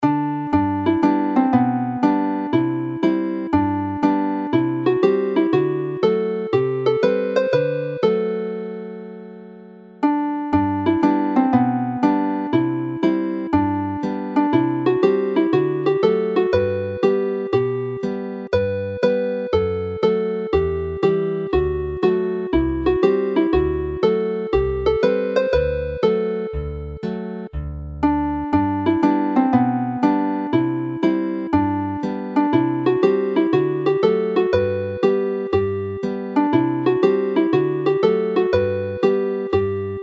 Midi